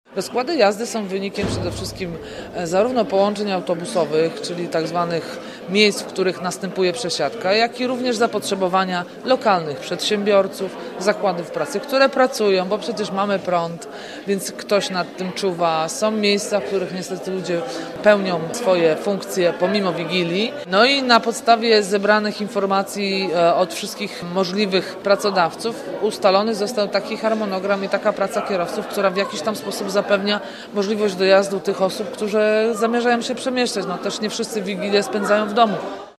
Jeden autobus na godzinę to jednak zbyt mało, ale ubiegłoroczne ograniczenia zachowamy – mówi wiceprezydent Agnieszka Surmacz: